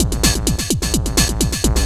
DS 128-BPM B5.wav